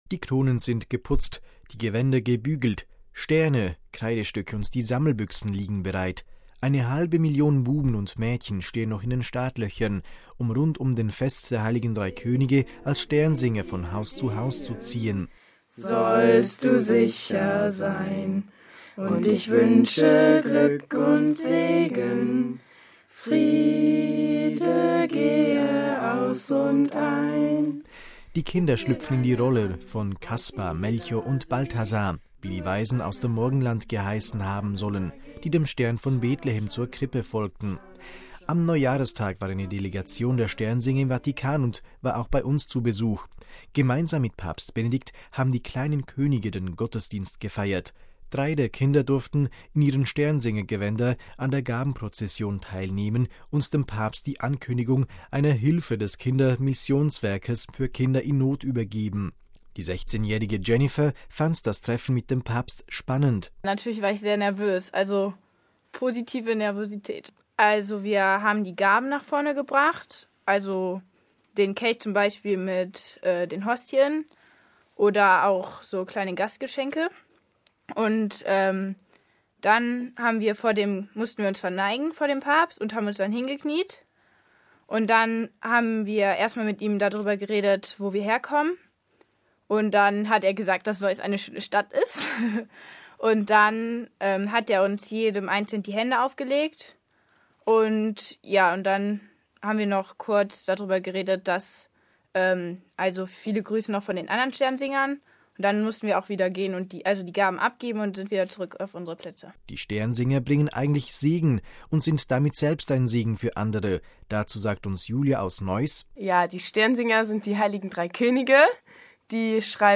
Hören Sie hier einen Beitrag